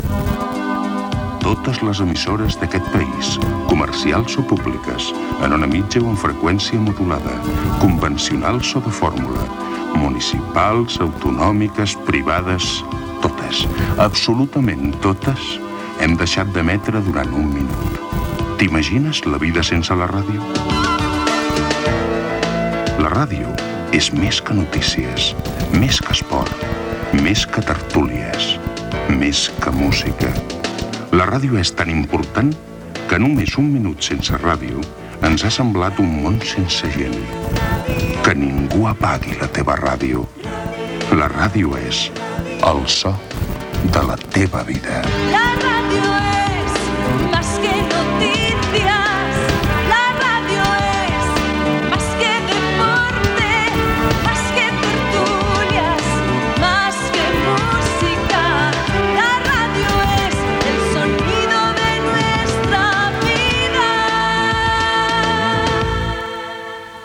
Anunci emès després del minut de silenci de totes les ràdios de l'Estat espanyol.